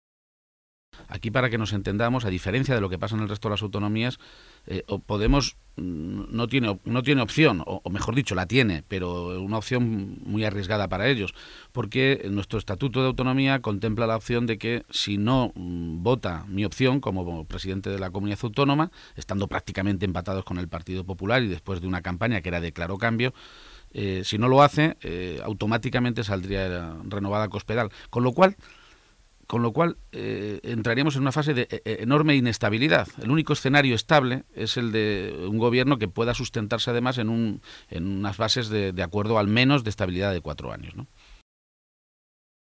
Cortes de audio de la rueda de prensa
Audio Page-entrevista Onda Cero 1